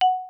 WallHit.wav